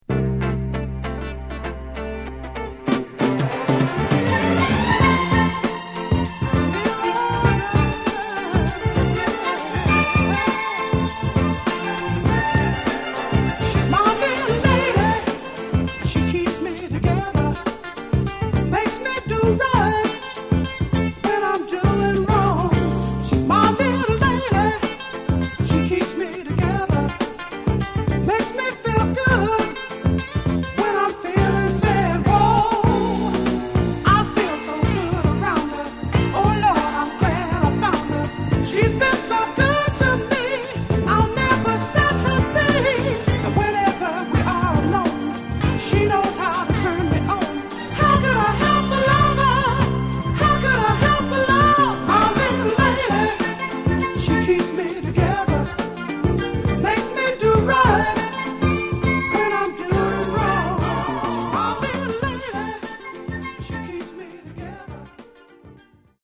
Genre: Northern Soul, Philly Style
This is a terrific Philly-Style soul dancer!